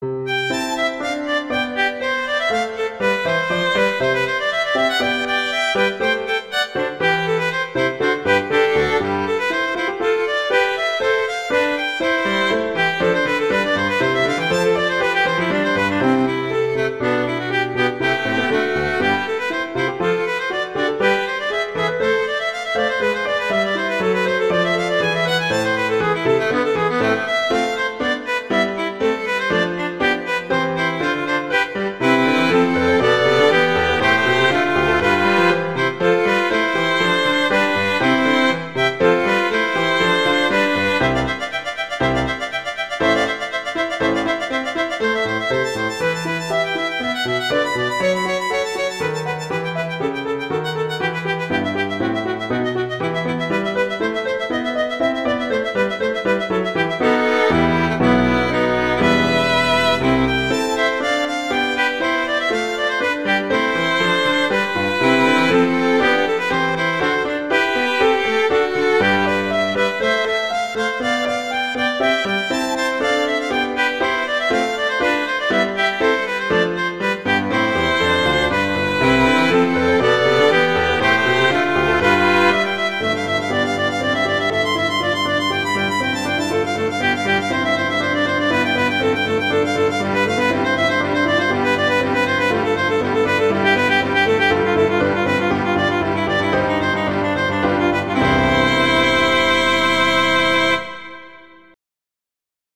violin and piano
classical
Allegro